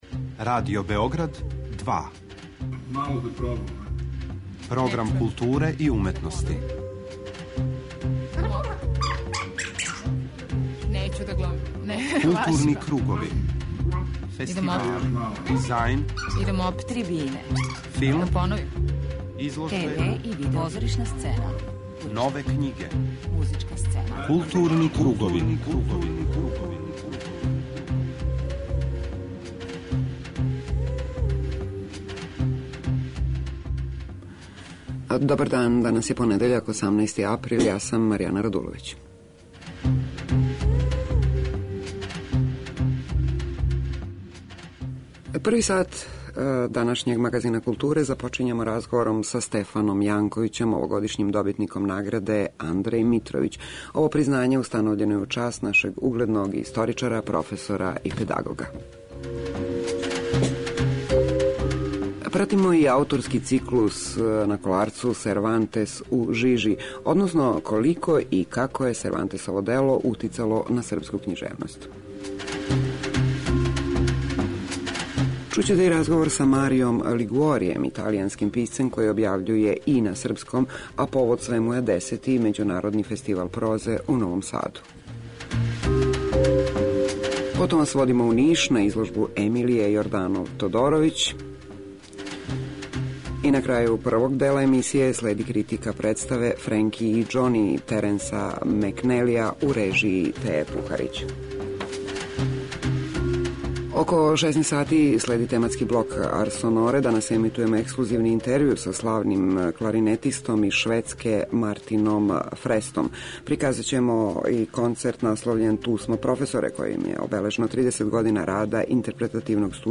У тематском блоку Арс сонора емитоваћемо ексклузиван интервју са славним кларинетистом из Шведске Мартином Фрестом, који је 15. априла свирао први пут са Београдском филхармонијом.